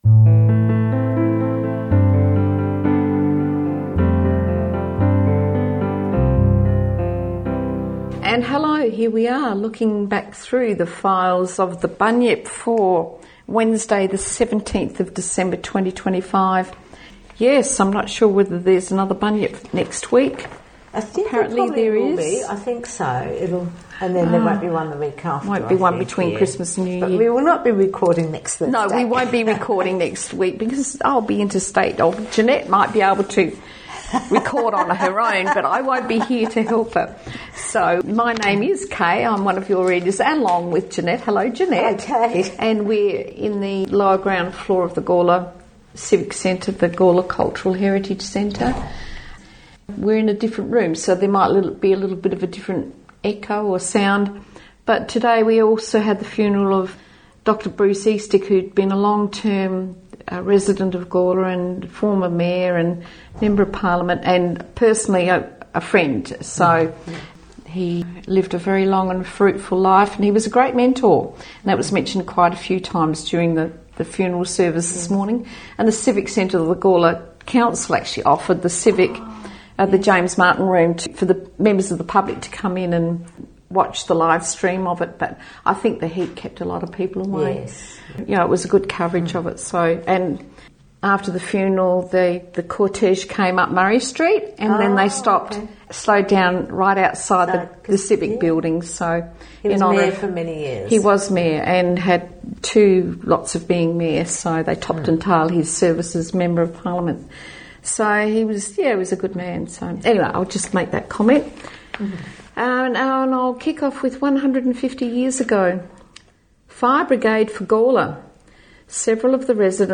Opening and closing music taken from A Tribute to Robbie Burns by Dougie Mathieson and Mags Macfarlane